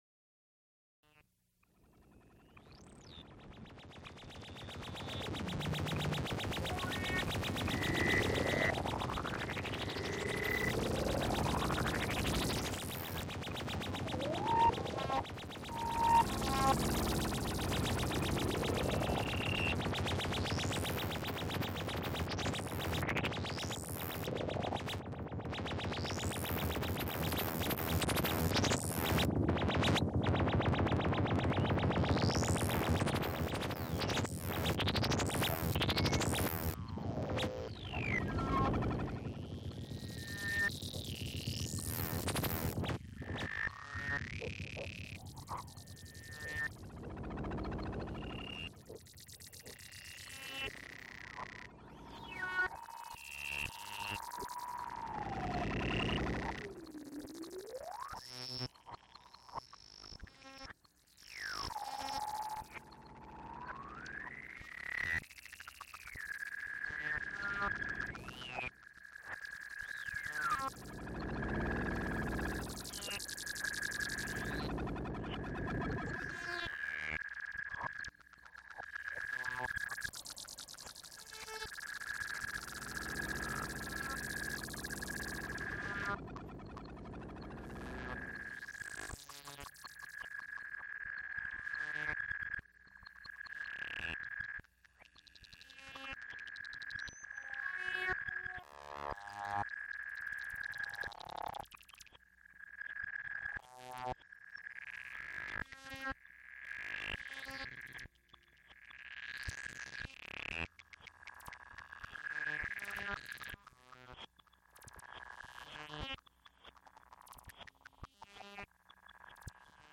A Concert of computer and electronic music | Digital Pitt
Artifice / Paul Lanksy ; Sketches / Tim Sullivan., Title from program., Program in container., Recorded June 26, 1980, Frick Fine Arts Auditorium, University of Pittsburgh., Artifice, Paul Lanksy; Sketches, Tim Sullivan., Risset, Jean-Claude.
Extent 2 audiotape reels : analog, half track, 15 ips ; 12 in.
Computer music Electronic music Trombone and electronic music